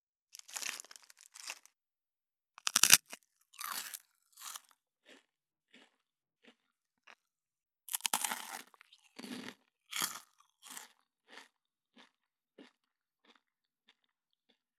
11.スナック菓子・咀嚼音【無料効果音】
ASMRスナック菓子効果音咀嚼音
ASMR